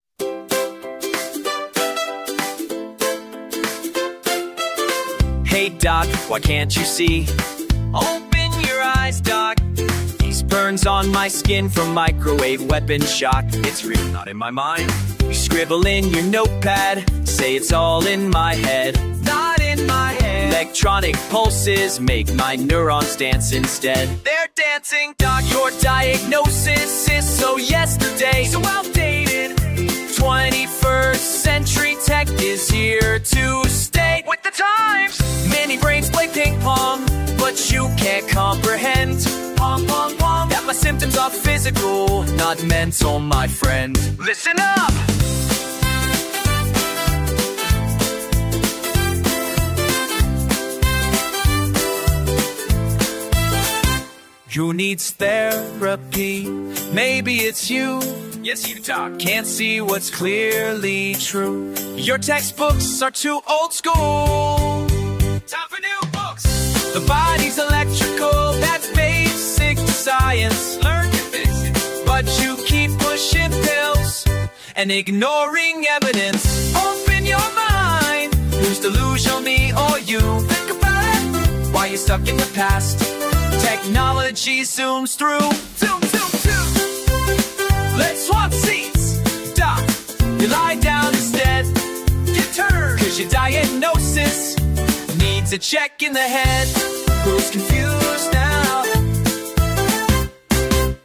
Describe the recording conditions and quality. ONE OF MANY, FREE MP3 CUSTOM PRODUCED WITH AI - MUSIC PROTESTS SONGS!